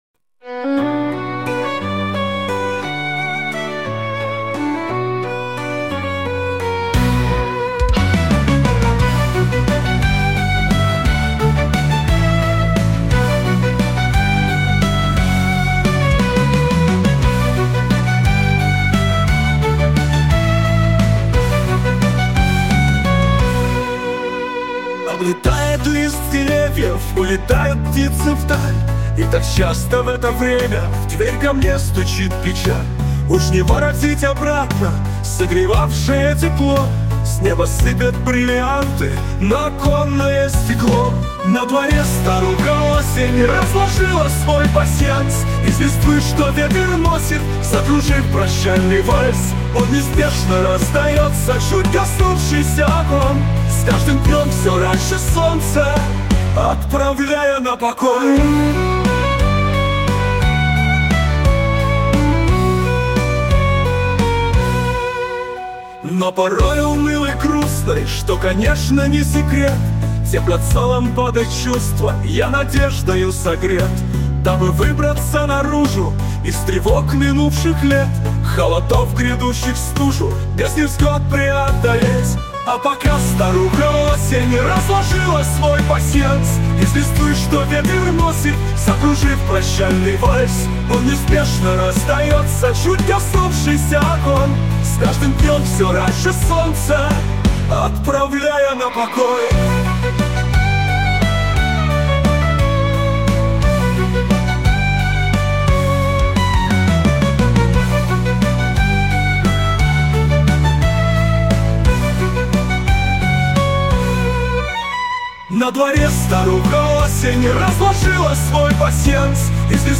Лирическая песня.